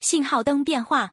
traffic_sign_changed.wav